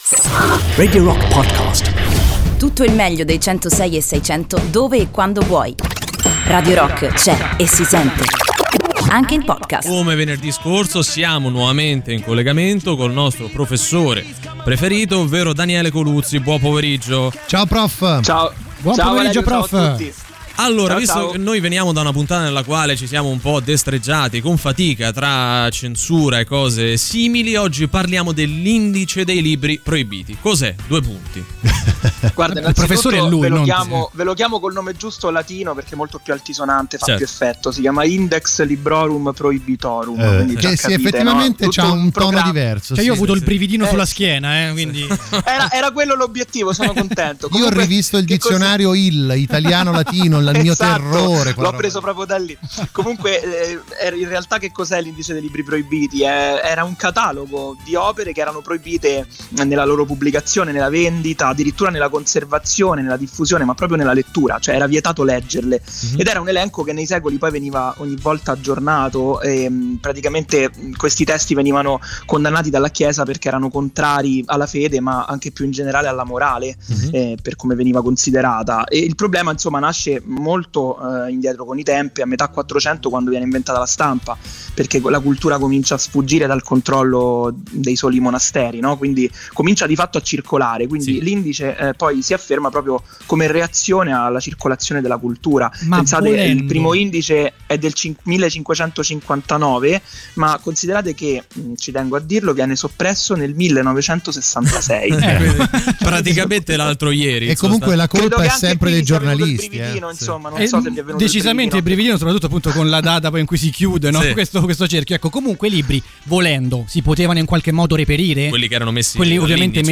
in diretta su Radio Rock